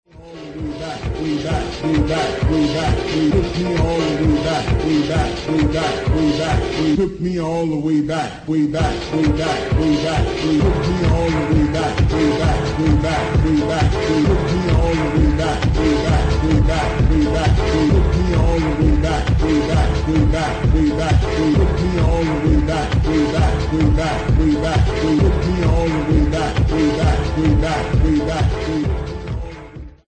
Great funkin' housemonster
House Detroit Vintage